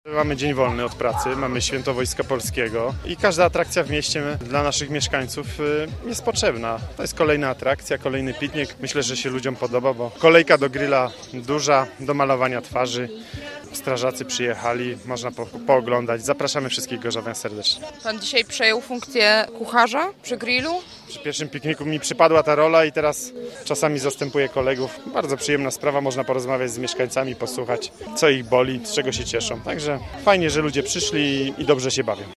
Piknik Rodzinny w parku Górczyńskim
– Powodzenie pierwszego pikniku skłoniło nas do tego, by zorganizować kolejny – mówi Sebastian Pieńkowski: